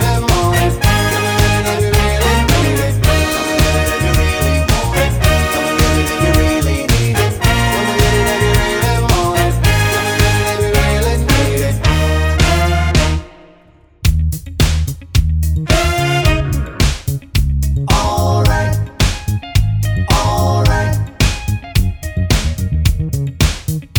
no Backing Vocals Dance 3:04 Buy £1.50